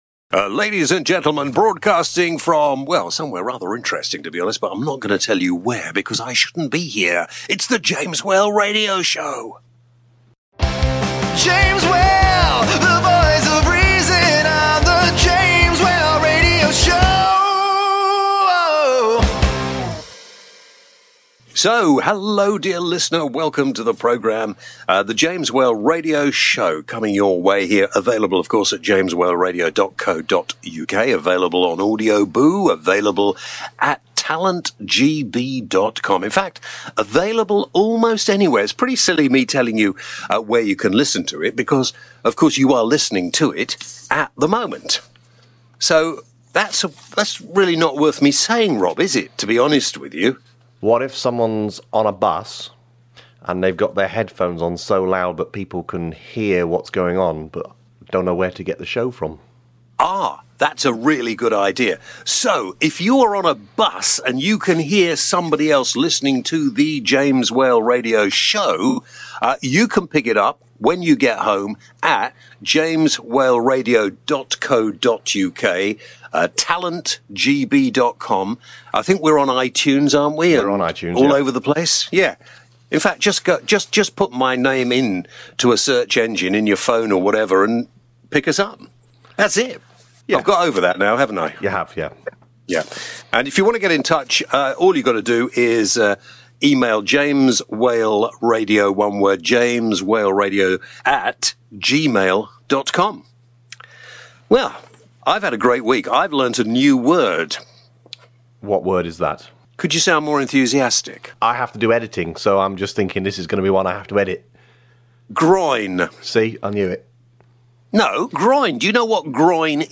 Danny John Jules – Actor (Red Dwarf / Death in Paradise and more) This week James chats to Danny John Jules Also on the show, Are we all racists?, Did we get the results we wanted in the euro elections, vouchers for obese people, and James wants us all to ...